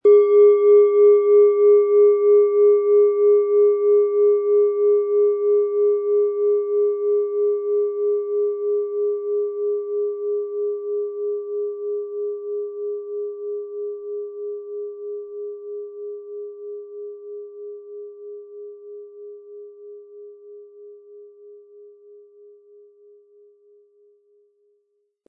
Von erfahrenen Meisterhänden in Handarbeit getriebene Klangschale.
Ein schöner Klöppel liegt gratis bei, er lässt die Klangschale harmonisch und angenehm ertönen.
PlanetentöneUranus & Saturn
MaterialBronze